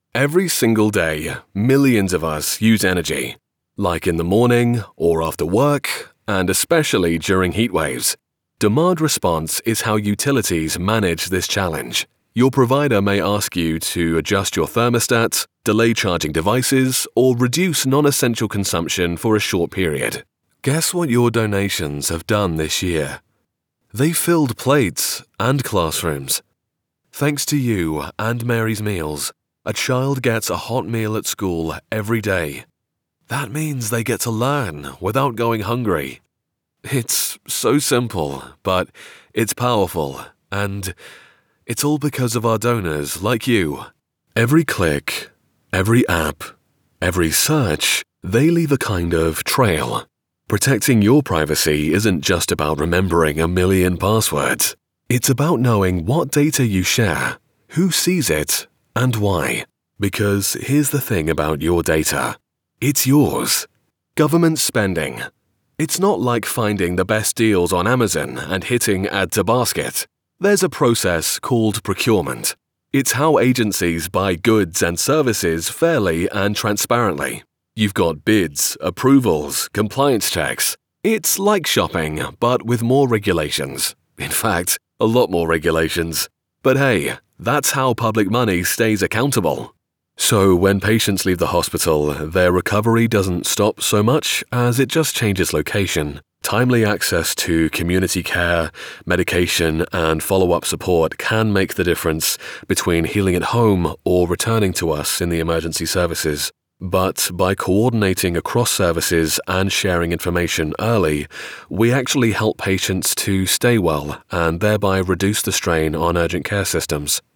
Inglés (Británico)
Profundo, Cool, Versátil, Natural, Llamativo
Explicador